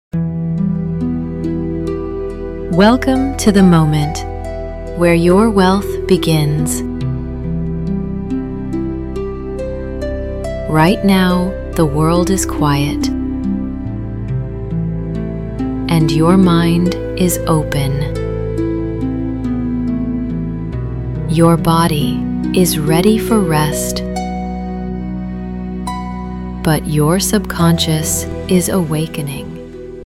Guided Meditations
Personalized guided meditations designed to manifest love, abundance, and inner peace. Voice-guided sessions with healing background frequencies and affirmations.